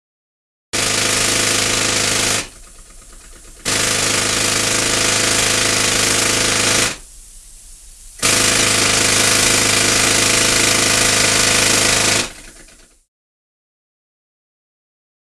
Jack Hammer; Jack Hammer Running With Air Line Hiss In03